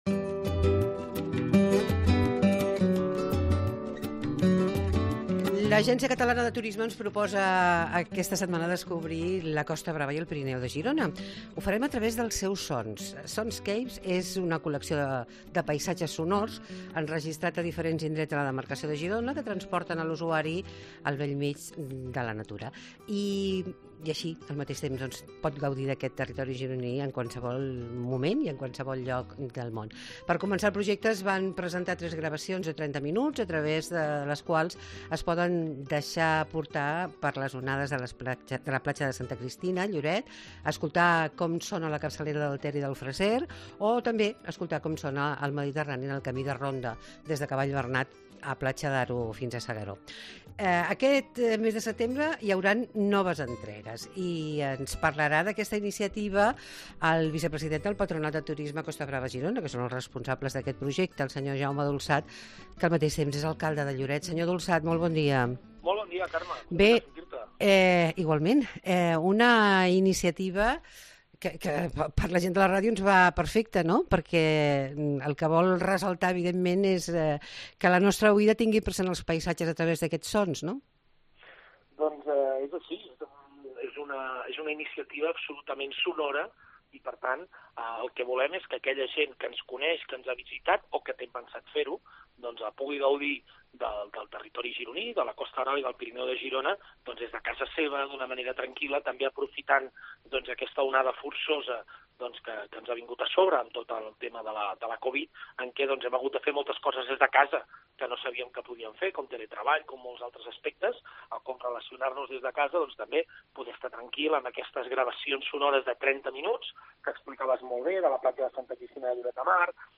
Tots els caps de setmana fem tres hores de ràdio pensades per aquells que els agrada passar-ho bé en el seu temps d'oci, on?